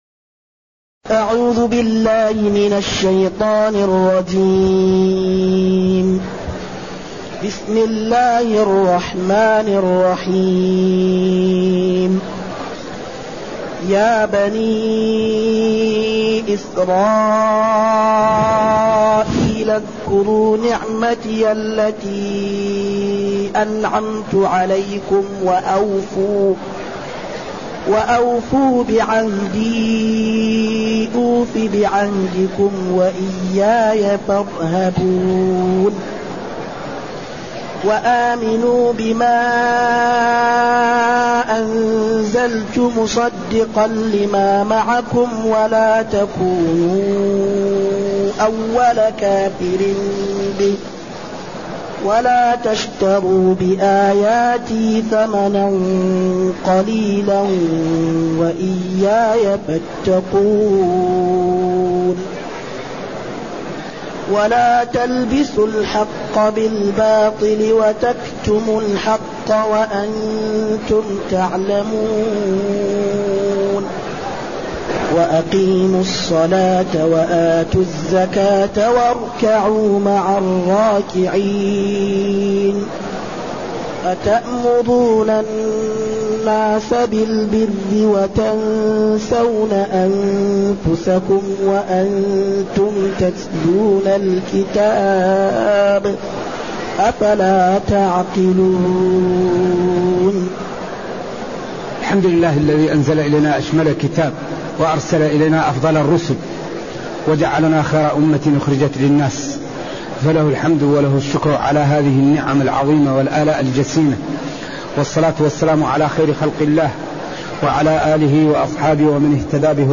تاريخ النشر ٣ محرم ١٤٢٨ هـ المكان: المسجد النبوي الشيخ